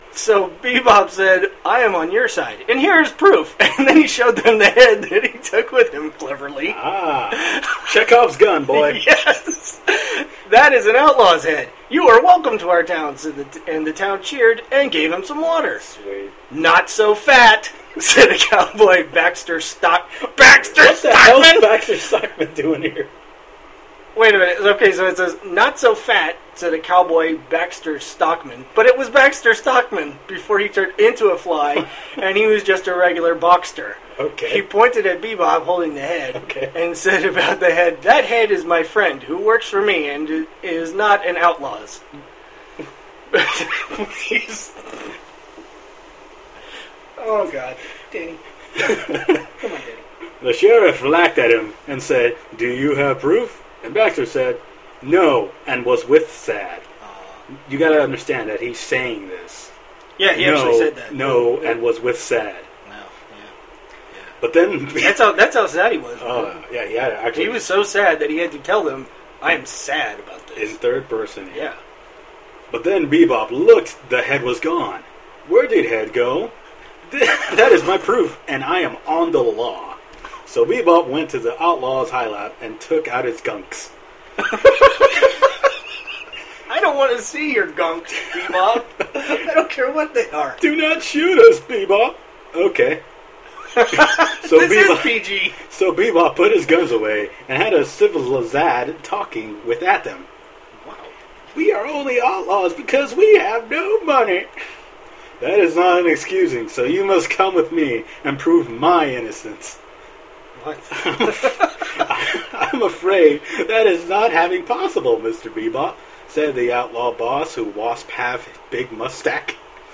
As a replacement, have another audio reading!